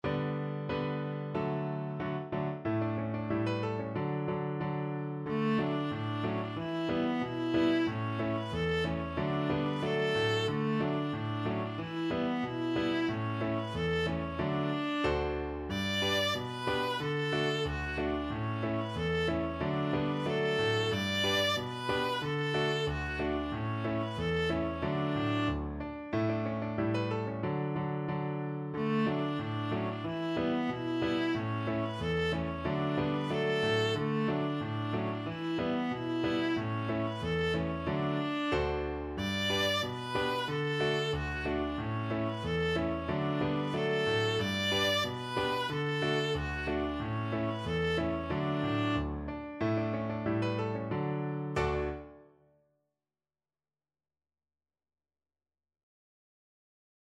Classical Trad. Vo Luzern uf Waggis zue Viola version
2/2 (View more 2/2 Music)
D major (Sounding Pitch) (View more D major Music for Viola )
Jolly =c.92
Viola  (View more Easy Viola Music)
Classical (View more Classical Viola Music)
Swiss